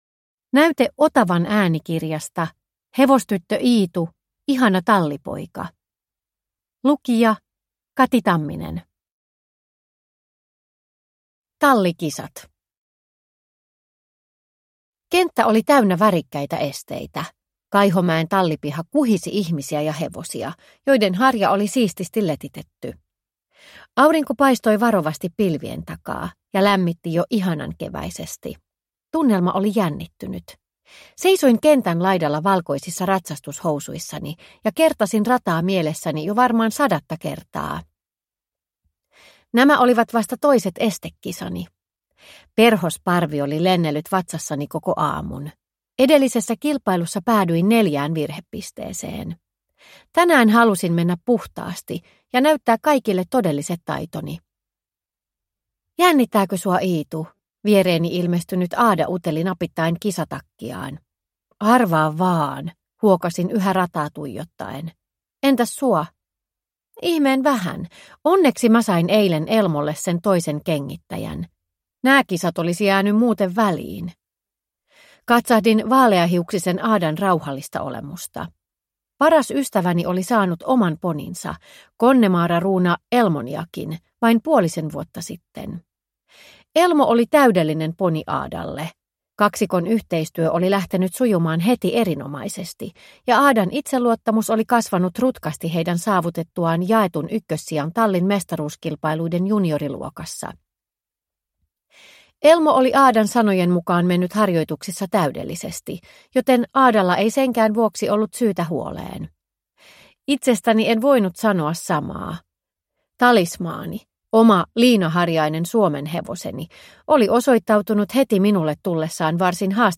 Ihana tallipoika – Ljudbok – Laddas ner